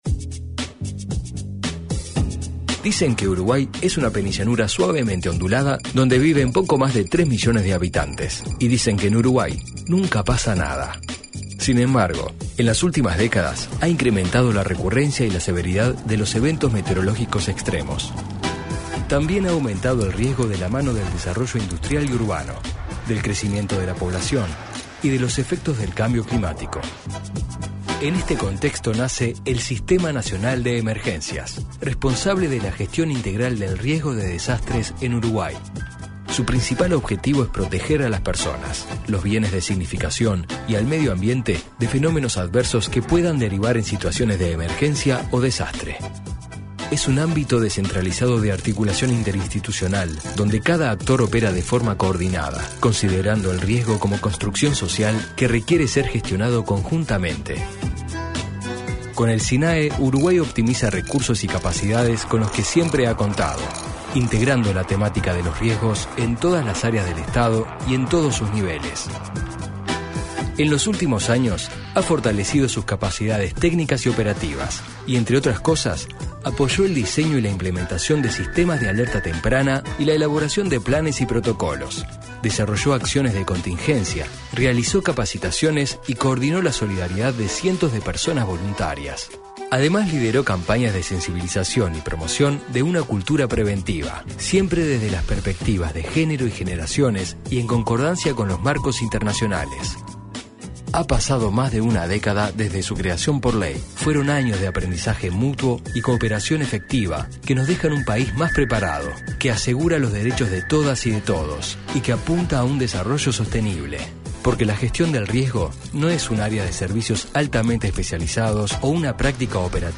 Lanzamiento de la VIII Plataforma Regional para la Reducción de Riesgo de Desastres 11/10/2022 Compartir Facebook X Copiar enlace WhatsApp LinkedIn Este martes 11, en la presentación de la VIII Plataforma Regional para la Reducción de Riesgo de Desastres, realizada en el salón de actos de la Torre Ejecutiva, participaron el director del Sistema Nacional de Emergencias (Sinae), Sergio Rico, y el jefe de la Oficina de Naciones Unidas para la Reducción del Riesgo de Desastres, Raúl Salazar.